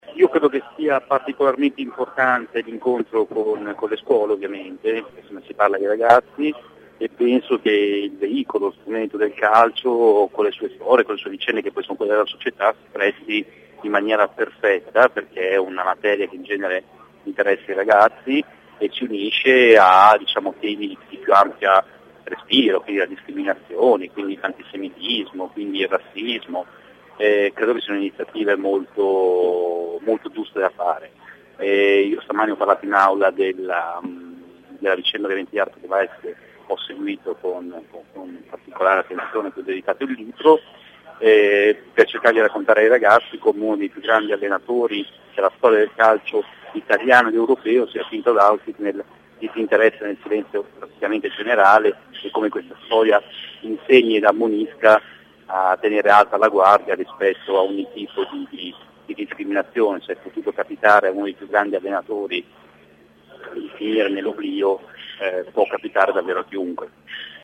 Intervista integrale